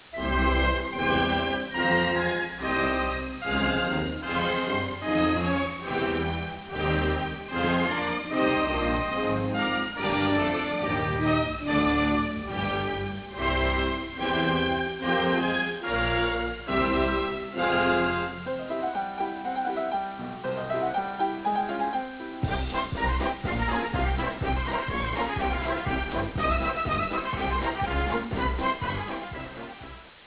Musica:
Original Track Music